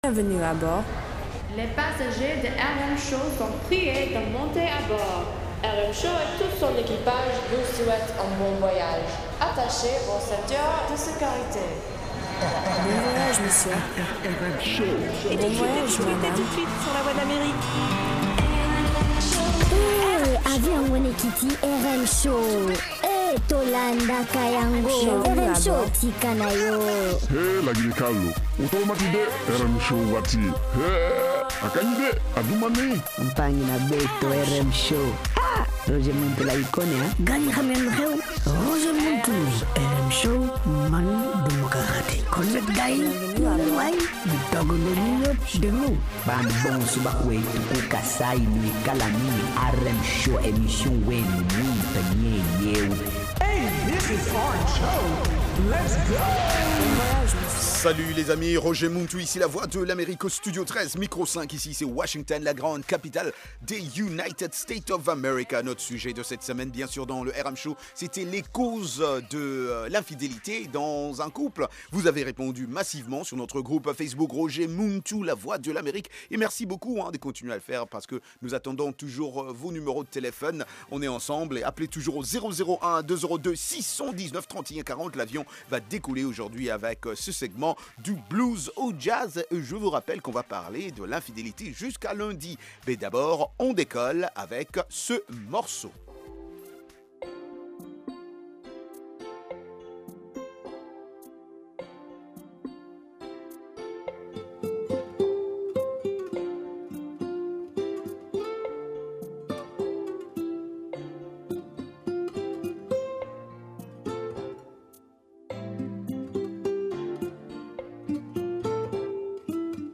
Blues and Jazz Program